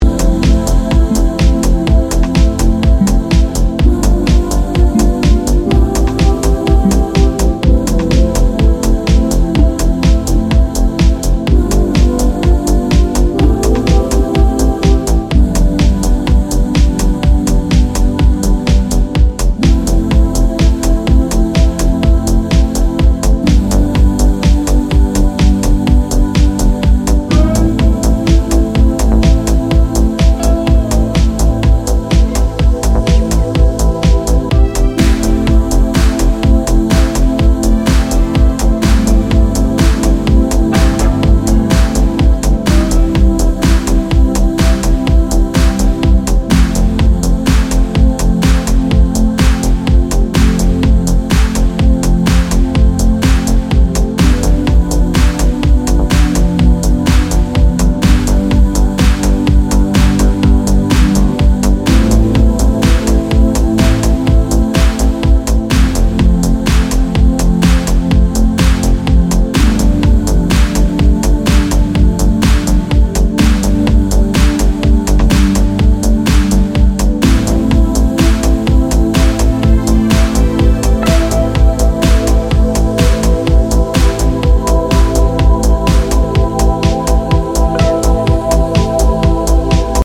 pop-leaning music field